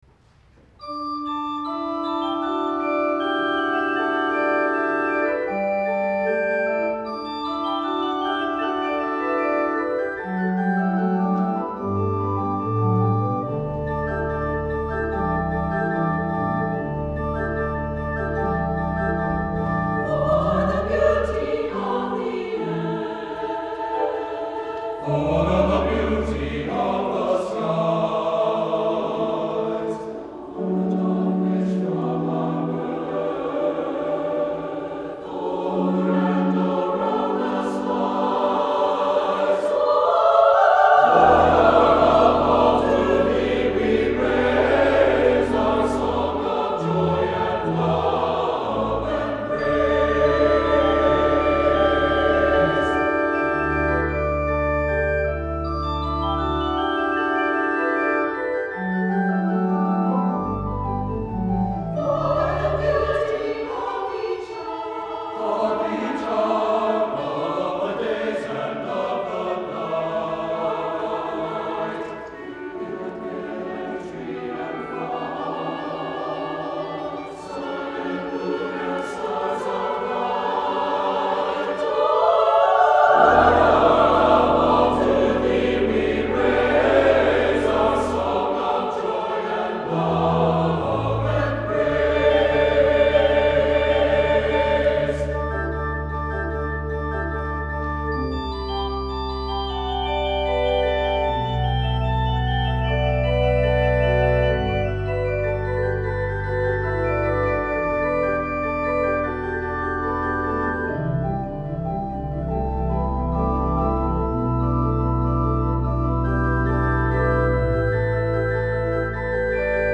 for SATB Chorus and Organ (2009)